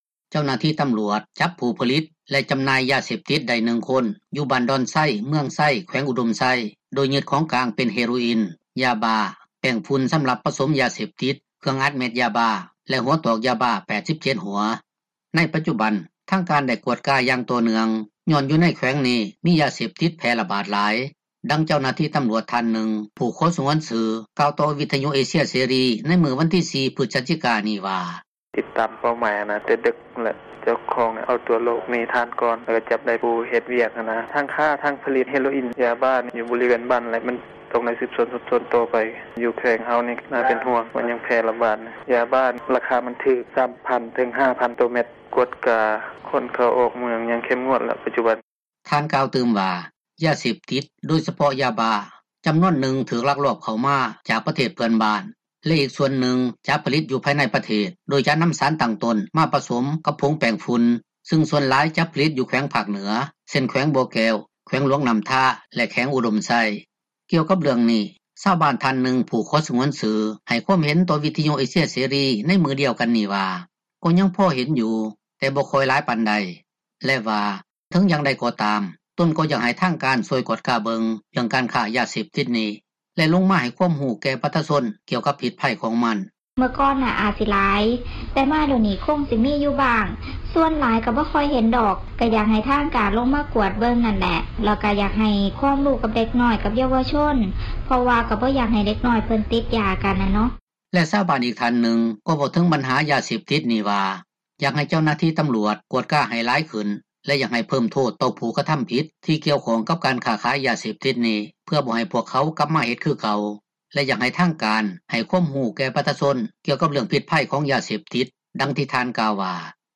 ນັກຂ່າວພົລເມືອງ